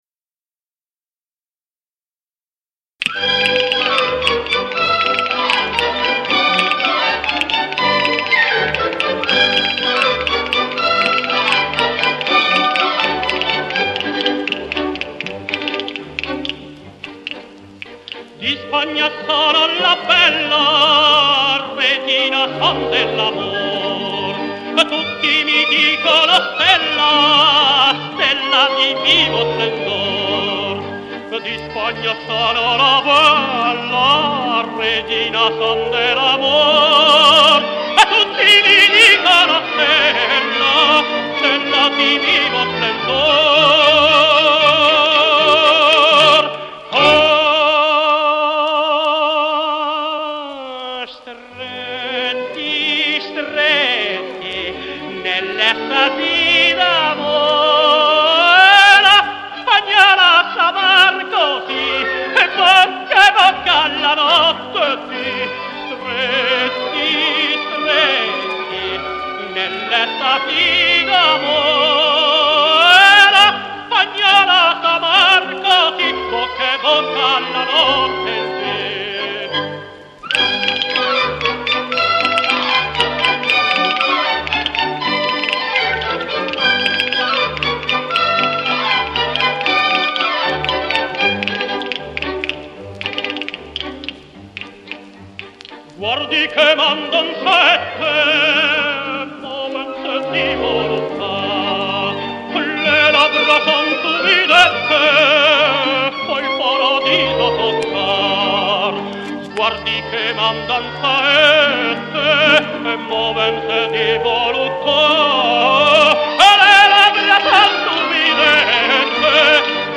con accompagnamento d'orchestra